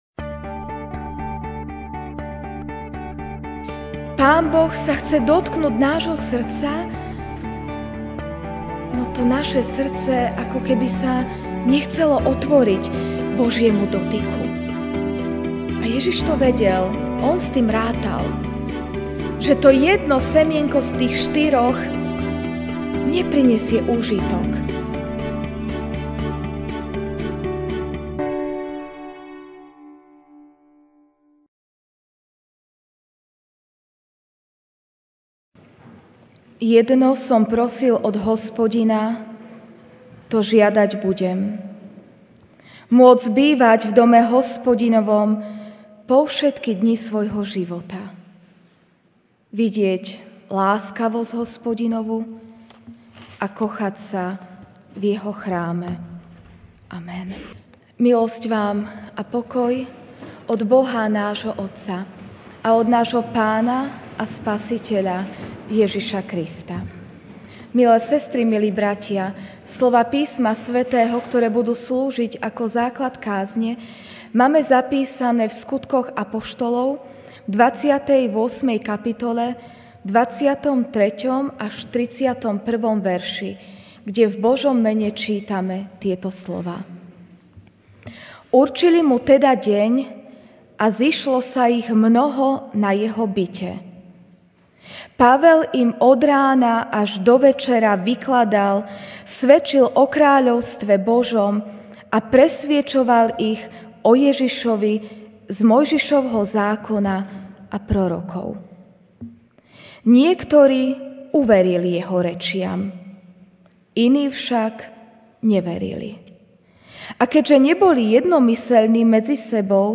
apr 28, 2019 Otvor mi oči Duch Svätý MP3 SUBSCRIBE on iTunes(Podcast) Notes Sermons in this Series Ranná kázeň: Otvor mi oči Duch Svätý (Sk 28, 23-31) Určili mu teda deň a zišlo sa ich mnoho na jeho byte.